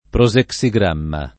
vai all'elenco alfabetico delle voci ingrandisci il carattere 100% rimpicciolisci il carattere stampa invia tramite posta elettronica codividi su Facebook prosexigramma [ pro @ ek S i g r # mma ] s. m. (psicol.); pl.